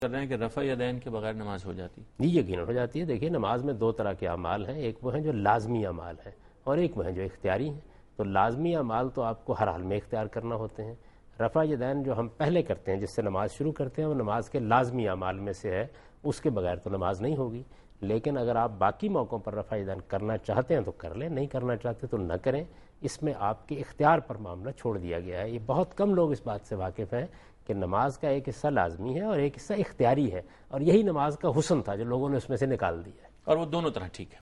Answer to a Question by Javed Ahmad Ghamidi during a talk show "Deen o Danish" on Duny News TV
دنیا نیوز کے پروگرام دین و دانش میں جاوید احمد غامدی ”رفع یدین کرنا“ سے متعلق ایک سوال کا جواب دے رہے ہیں